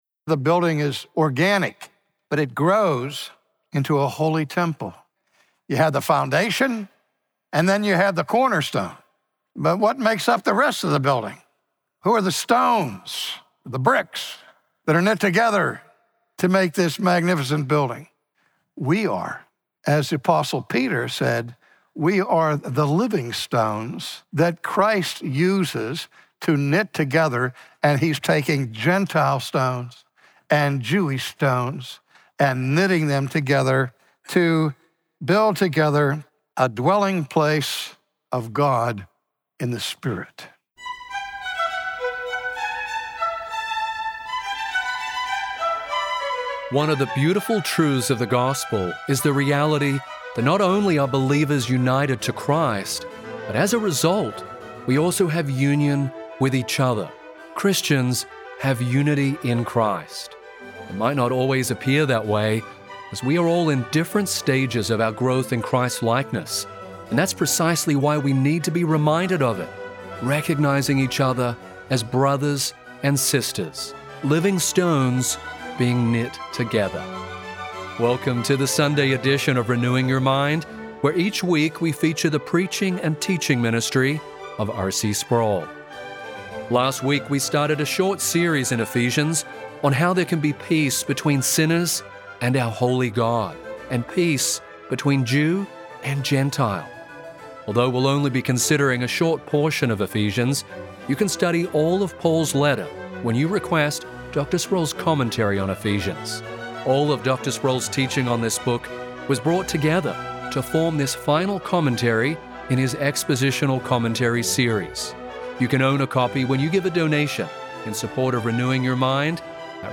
While the gospel grants us peace with God, we also obtain peace with one another through our union with Christ. From his sermon series in Ephesians, today R.C. Sproul explains how Jesus unites believing Jews and gentiles in His church to form a new humanity.